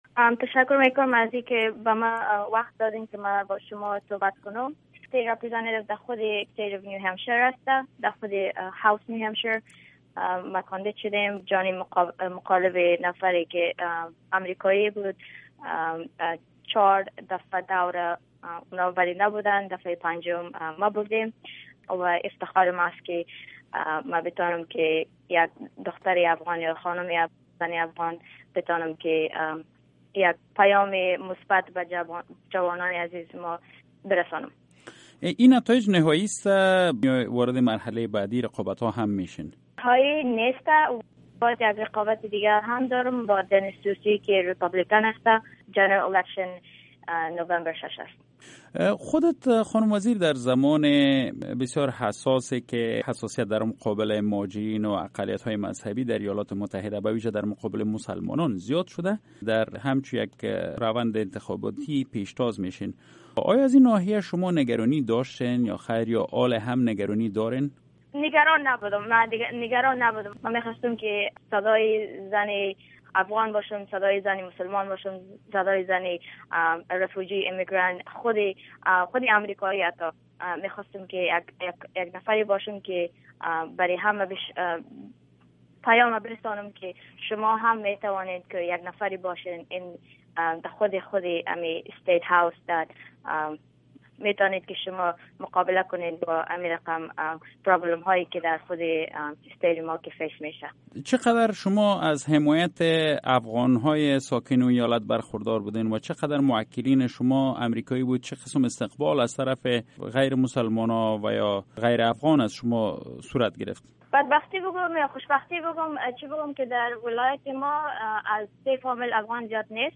مصاحبه با صفیه وزیر